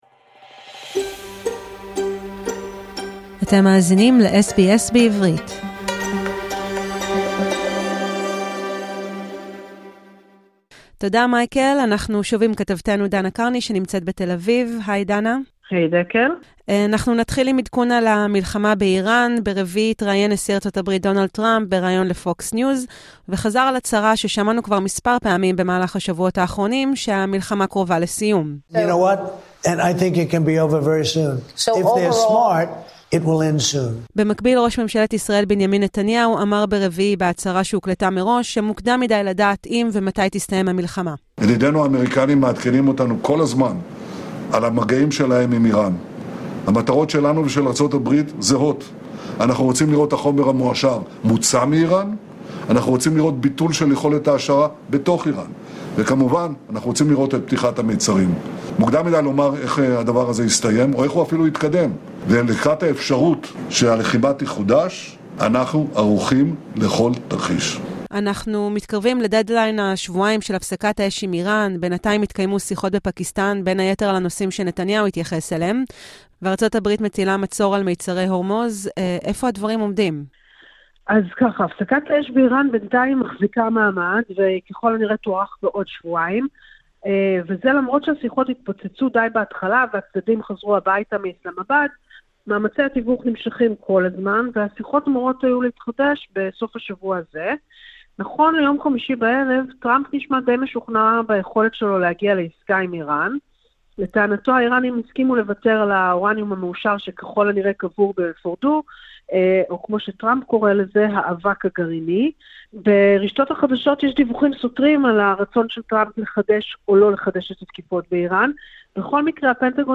המשדרת אלינו מתל אביב - בעדכון חדשות מהשבוע החולף.